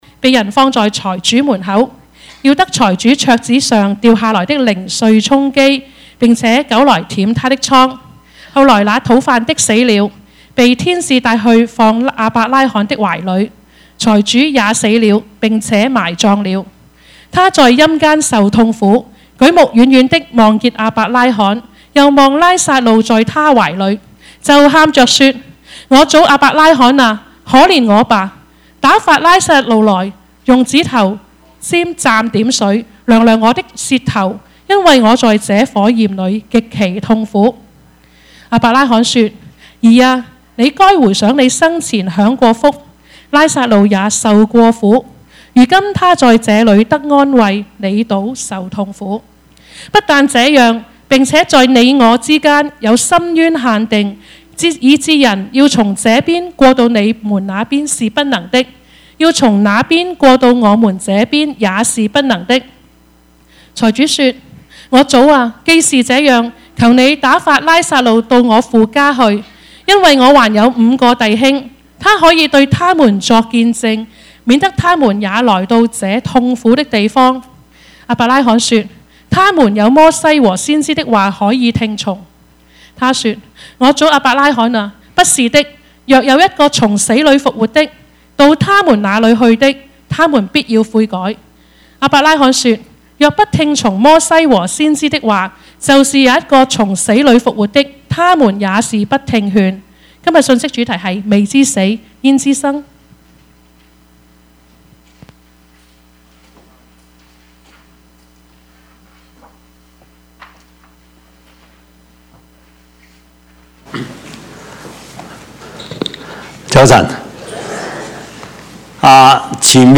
Passage: 路加福音 16:19-31 Service Type: 主日崇拜
Topics: 主日證道 « 選戰 人情味 »